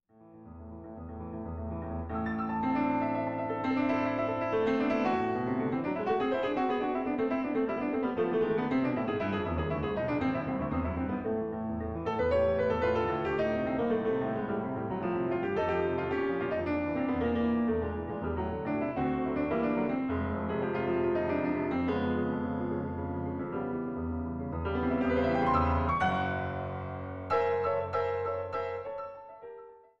Violine
Gitarre
Klavier
für Klavier